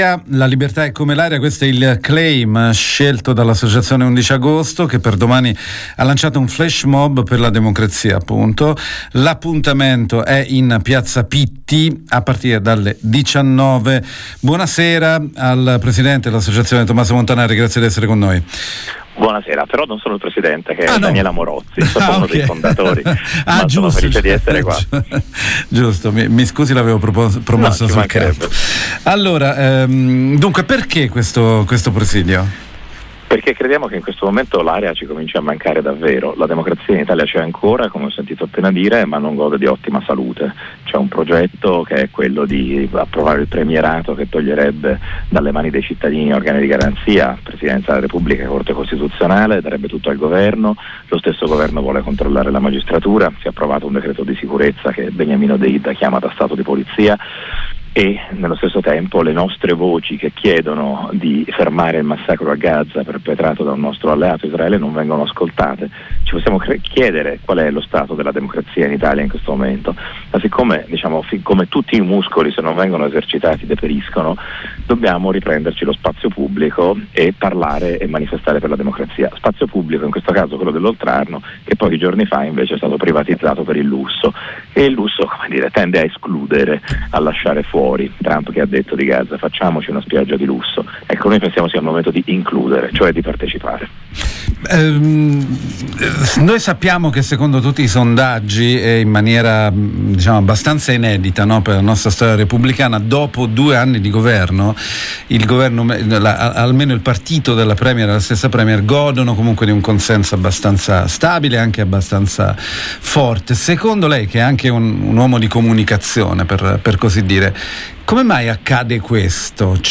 L’associazione XI Agosto lancia un flash mob per la democrazia. Abbiamo intervistato il prof. Tomaso Montanari